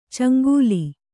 ♪ caŋgūli